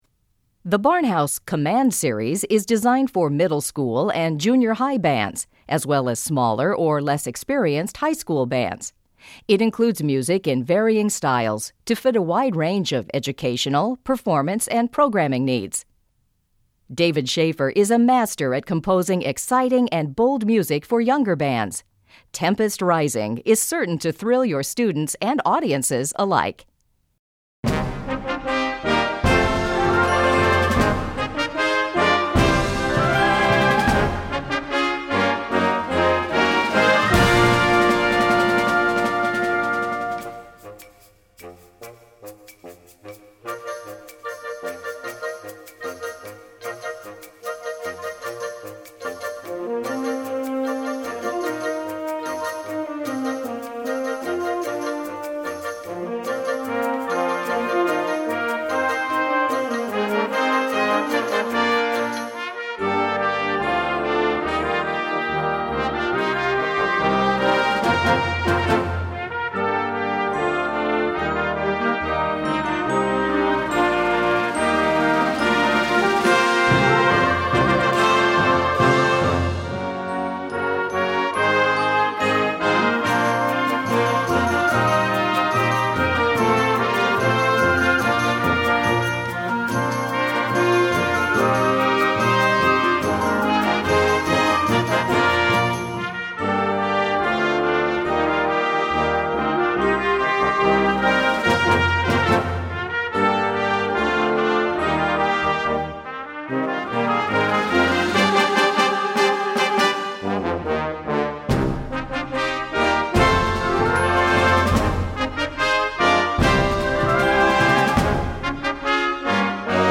Kategorie Blasorchester/HaFaBra
Unterkategorie Ouvertüre (Originalkomposition)